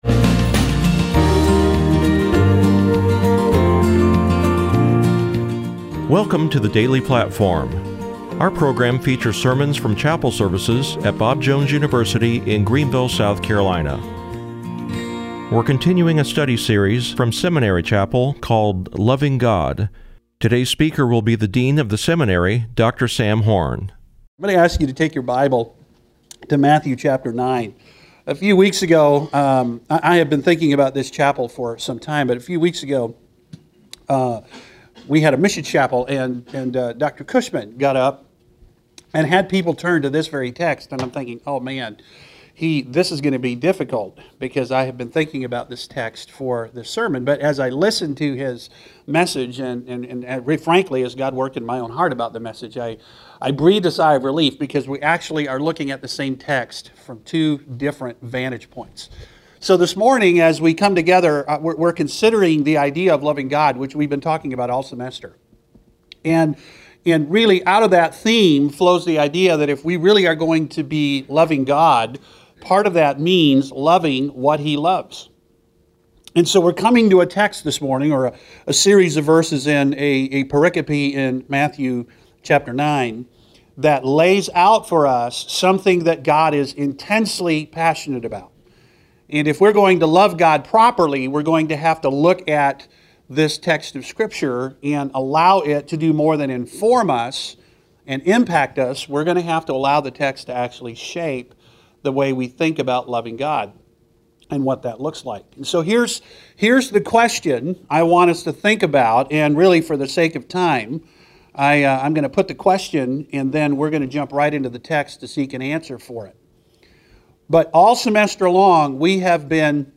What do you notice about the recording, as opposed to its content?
seminary chapel series